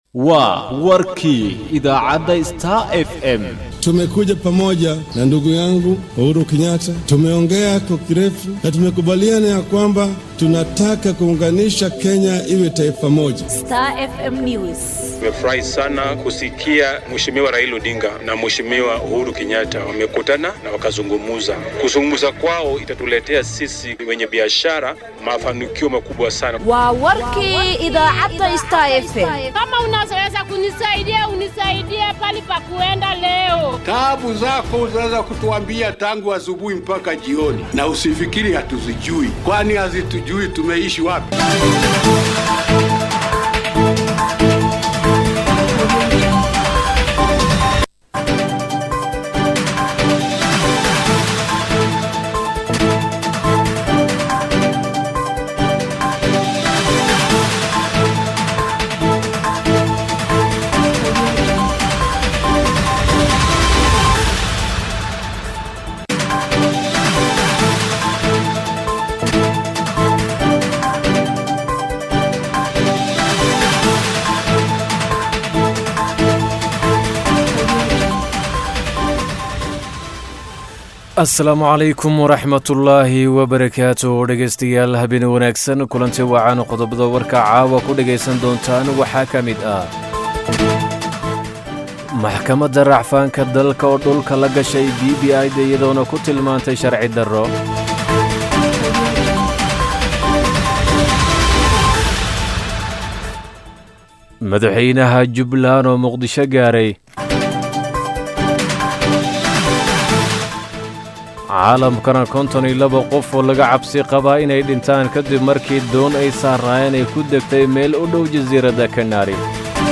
DHAGEYSO:WARKA HABEENIMO EE IDAACADDA STAR FM